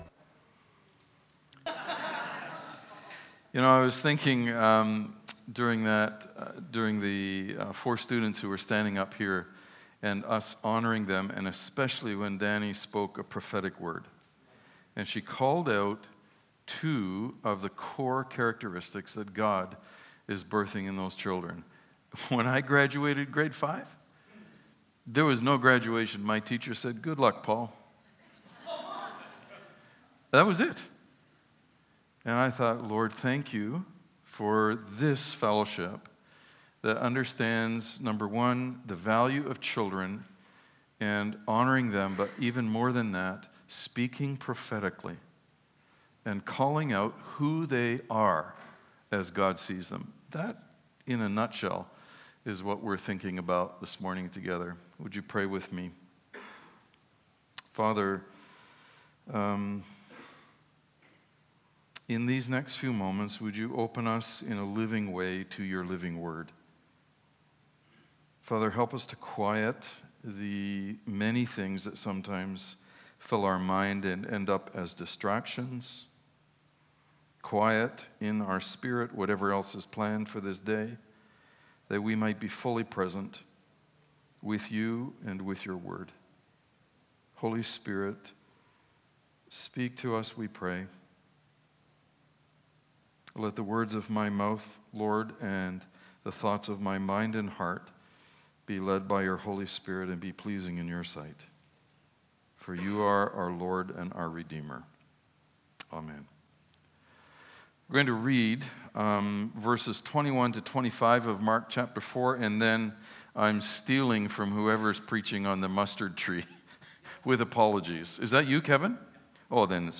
Sermons | Coast Hills Community Church